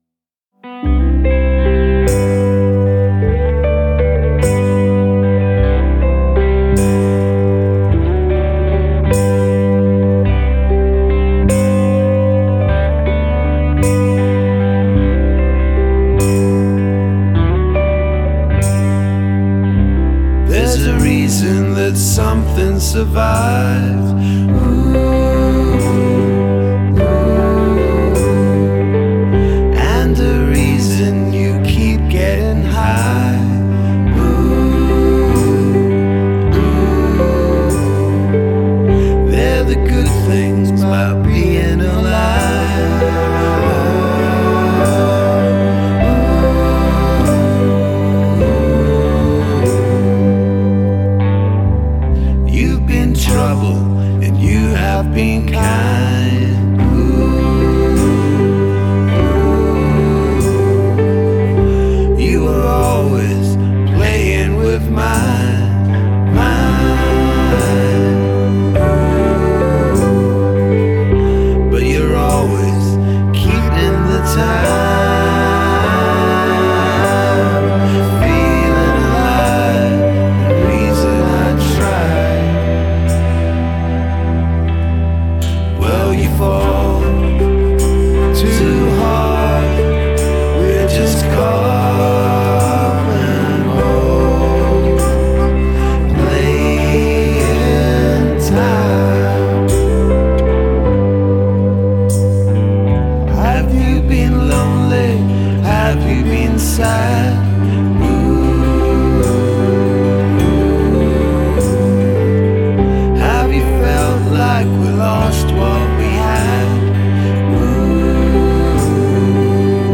Genre: acoustic, experimental, folk rock